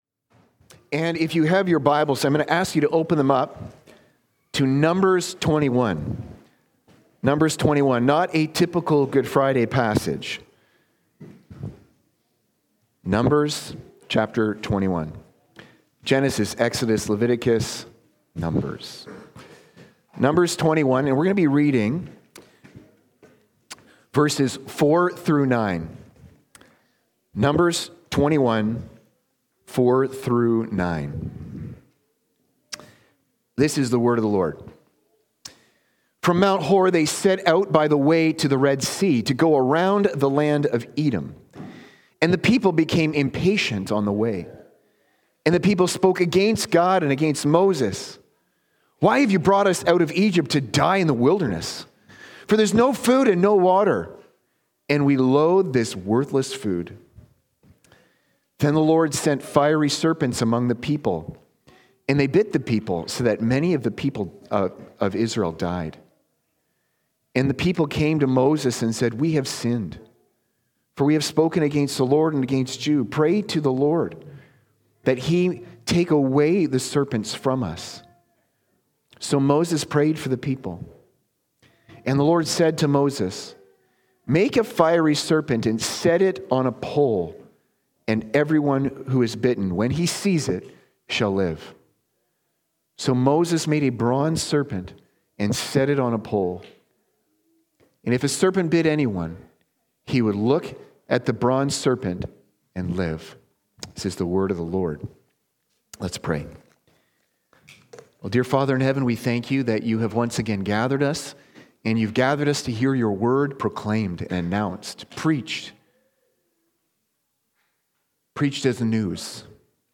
Sermons | Park City Gospel Church